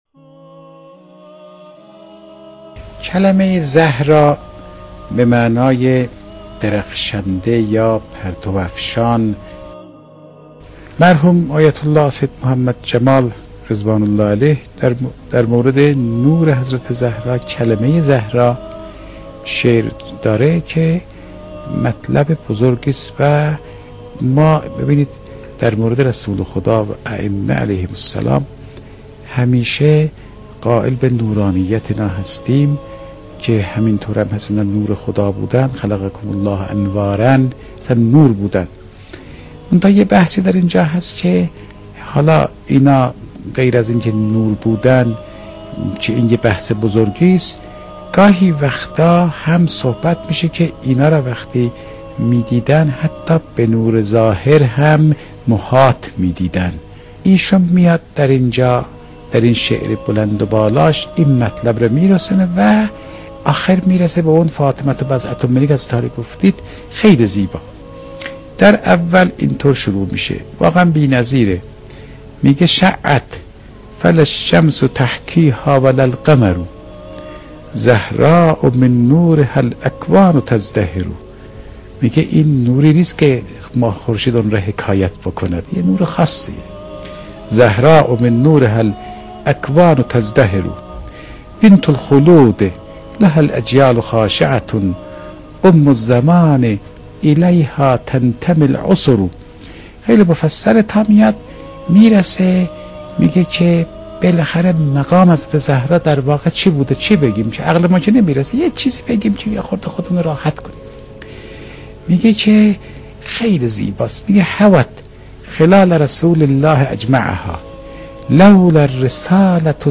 اگر می‌خواهید بدانید حضرت زهرا(س) که بوده است این را بدانیدکه تمامی صفات پیامبر‌(ص) را دارابودند. در ادامه بخشی از سخنرانی آیت‌الله فاطمی‌نیا تقدیم مخاطبان گرامی ایکنا می‌شود.